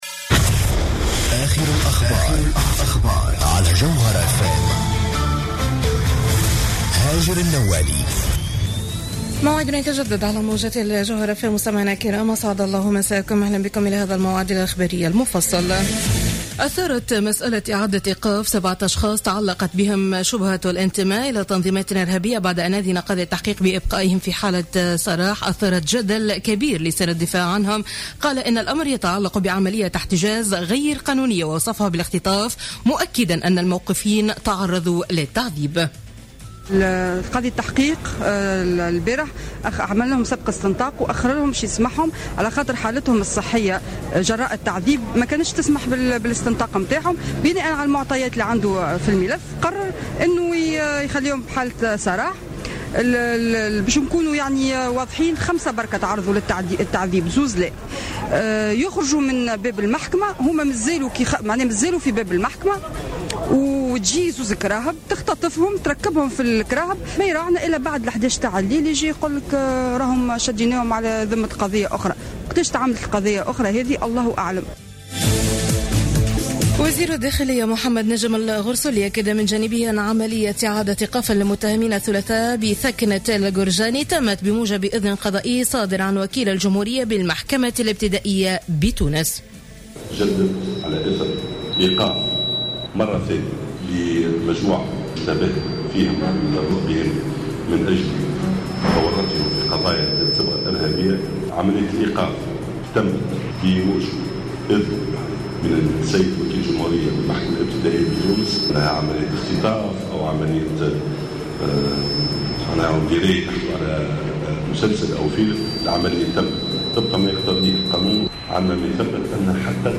نشرة أخبار منتصف الليل ليوم الخميس 06 أوت 2015